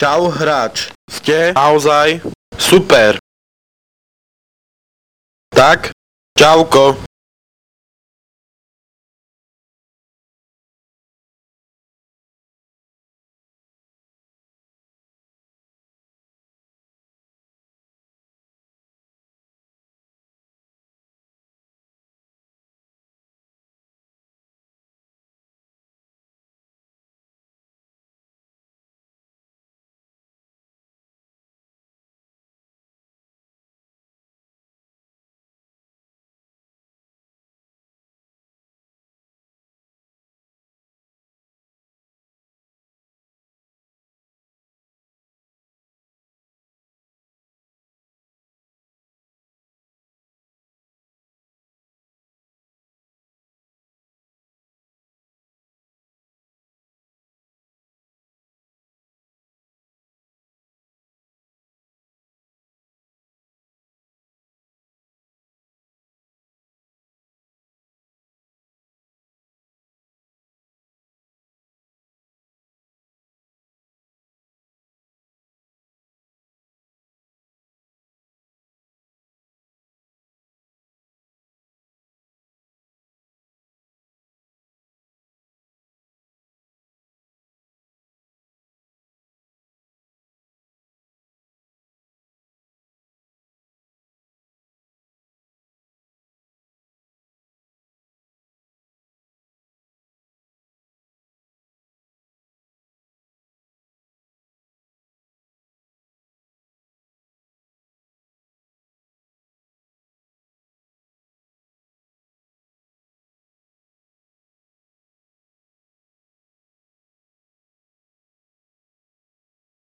Protracker Module
2 channels
Tracker Fast Tracker M.K.